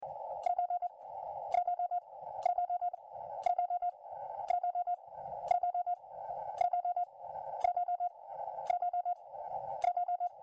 バグキーは二台所有しているがHi-MONDO BK-100で打つ時のみチャタリングが発生し音が濁る
こんな音です（チャタリング対策前MP3)